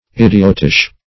Idiotish \Id"i*ot*ish\